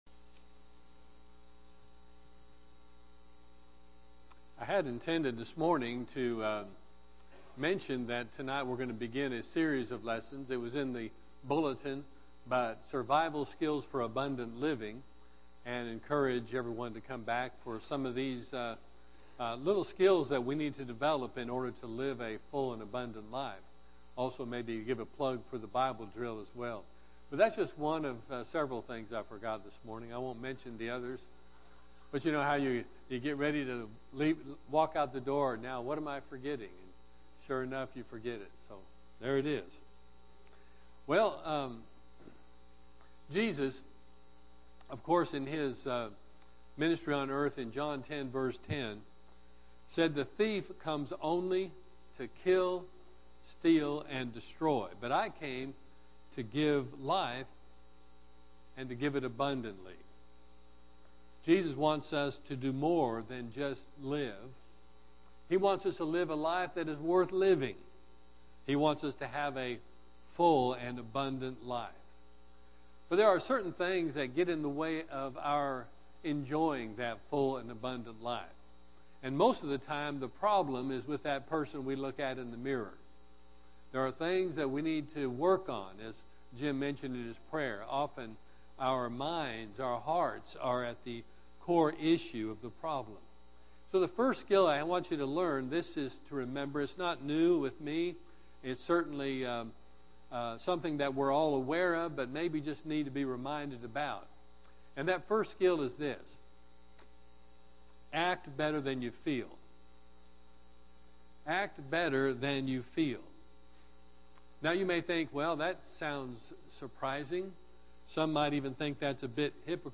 PM Sermon – Greenbrier church of Christ